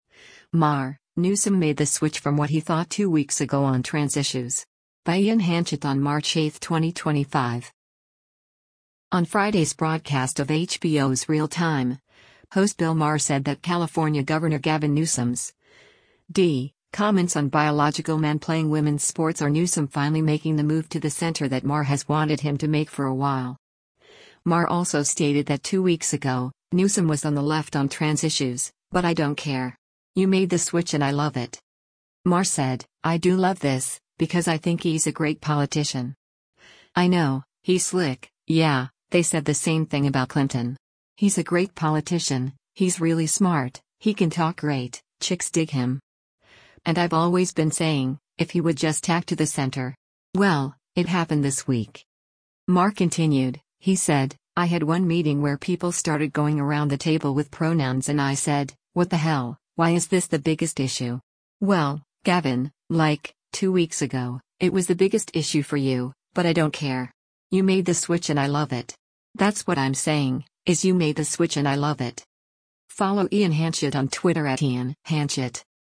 On Friday’s broadcast of HBO’s “Real Time,” host Bill Maher said that California Gov. Gavin Newsom’s (D) comments on biological men playing women’s sports are Newsom finally making the move to the center that Maher has wanted him to make for a while.